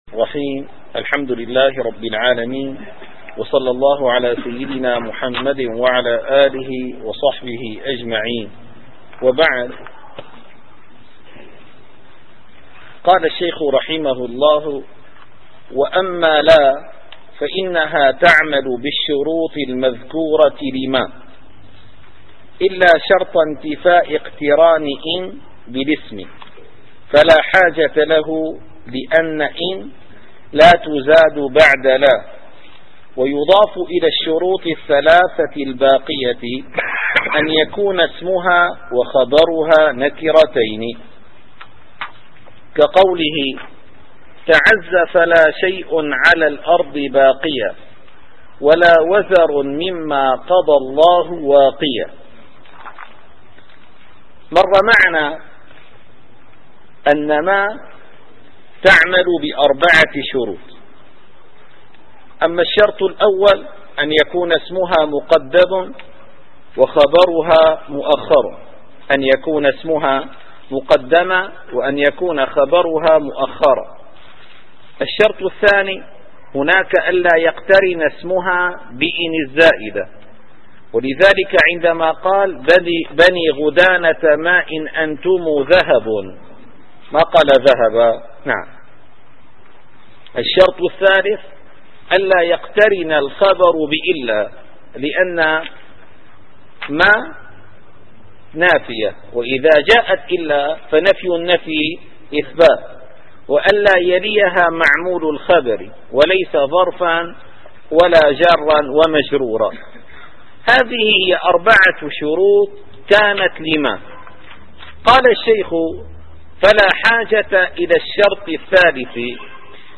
- الدروس العلمية - شذور الذهب في معرفة كلام العرب - شروط عمل لا عمل ليس ص259-276.